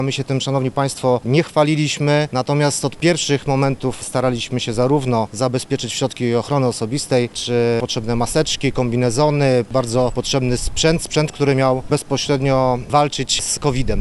Dzisiaj do Radomskiego Szpitala Specjalistycznego trafiła 15 transportowa, mówi wicemarszałek Rafał Rajkowski: